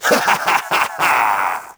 Dark Laugh.wav